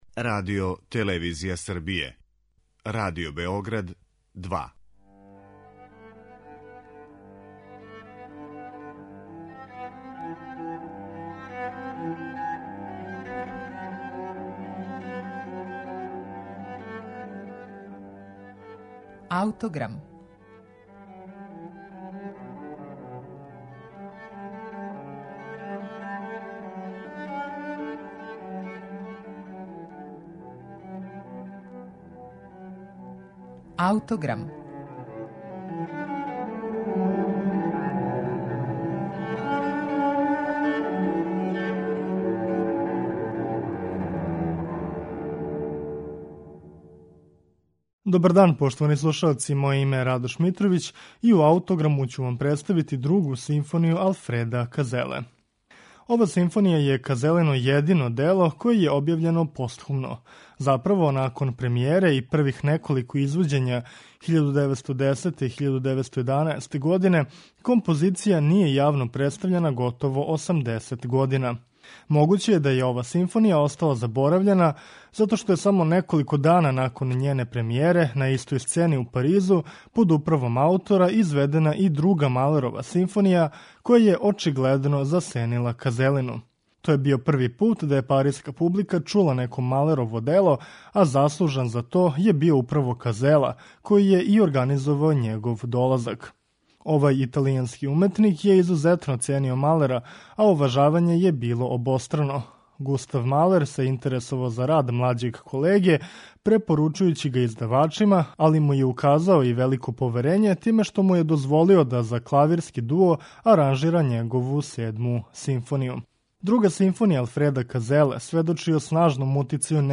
Монументална, петоставачна композиција сведочи о снажном утицају поетике Густава Малера на овог италијанског аутора, који је темељно проучавао стваралаштво старијег колеге.